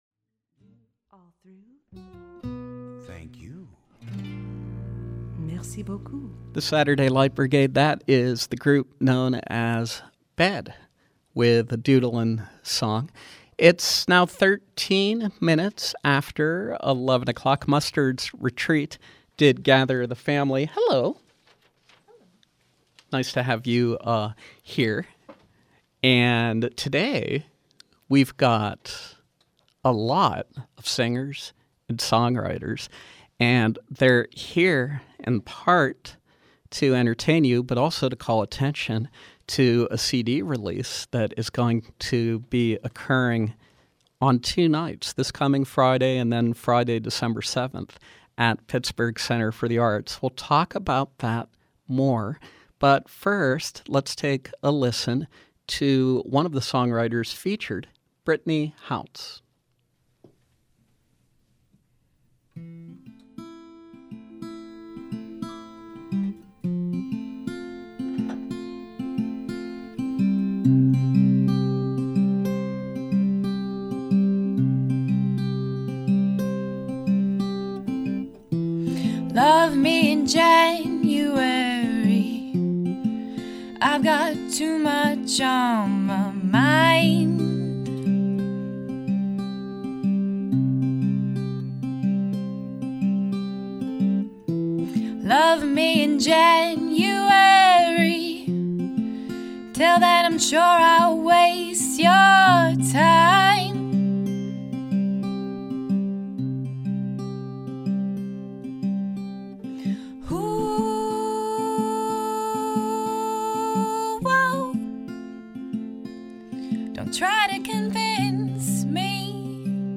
Live Music: Pittsburgh Songwriters Circle